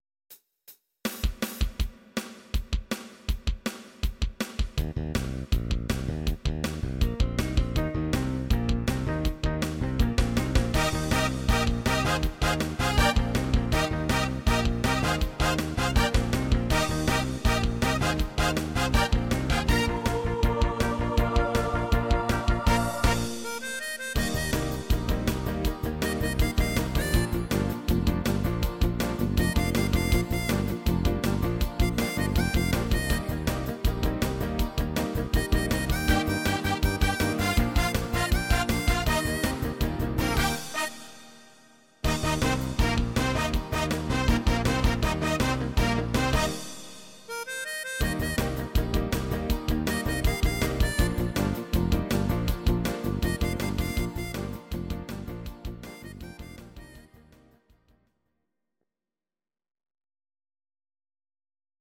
Audio Recordings based on Midi-files
Pop, 1960s